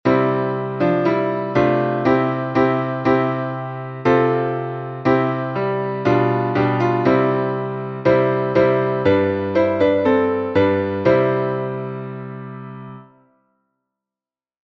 Сербский напев